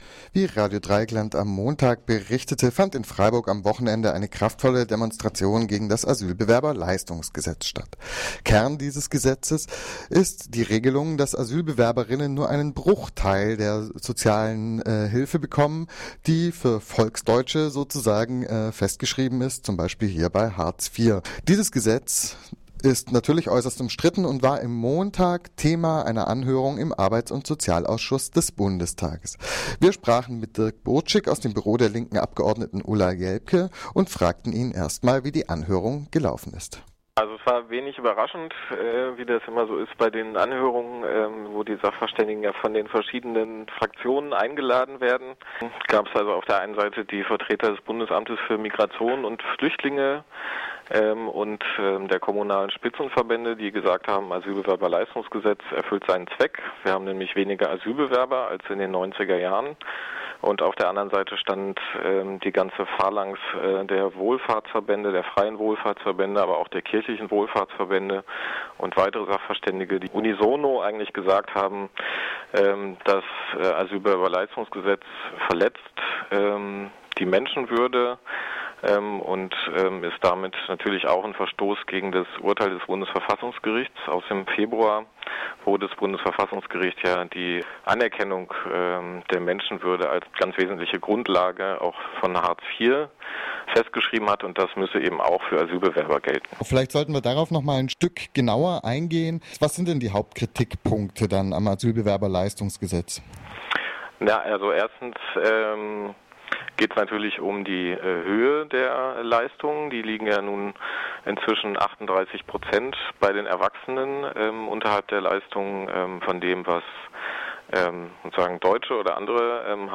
Interview zum Asylbewerberleistungsgesetz